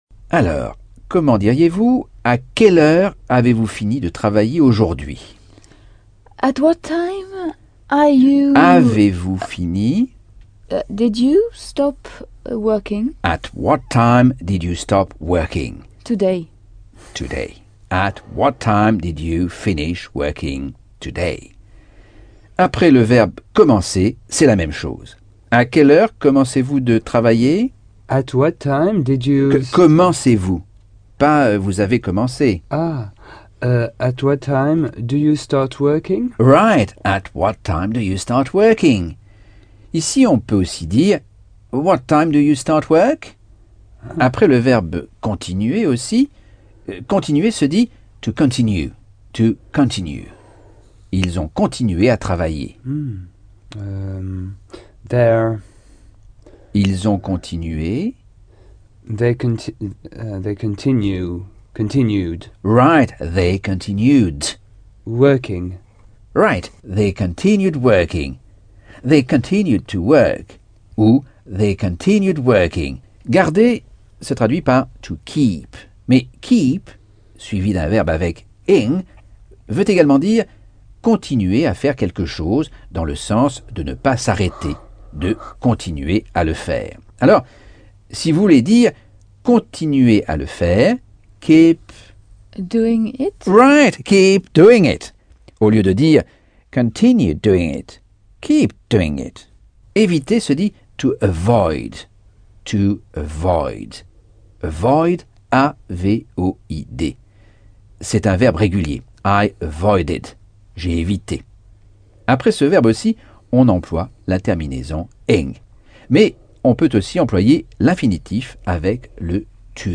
Leçon 9 - Cours audio Anglais par Michel Thomas - Chapitre 11